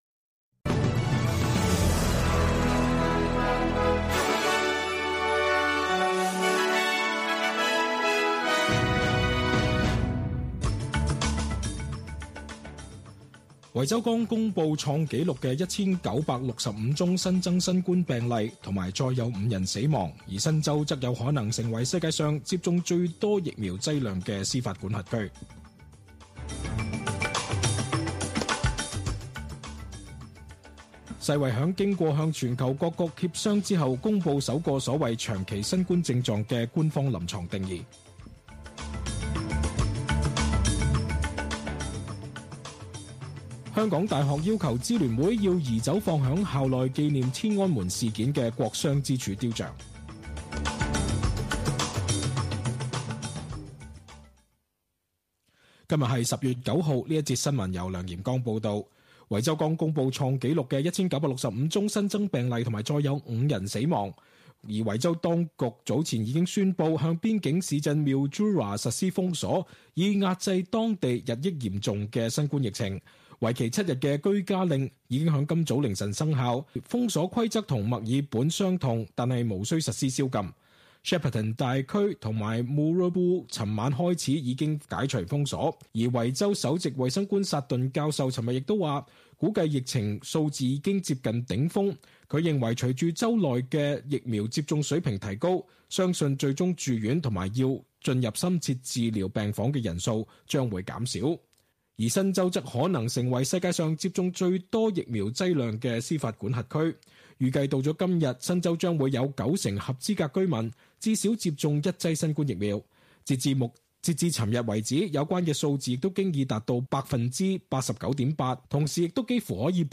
SBS中文新聞(10月9日)